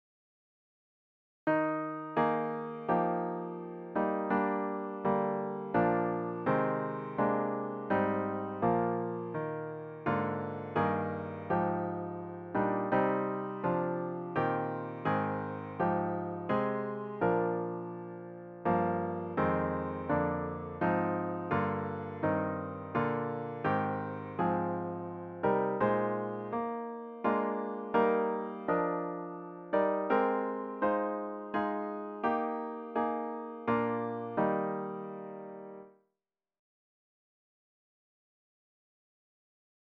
About the Hymn
The hymn should be performed at a lilting♩= ca. 84.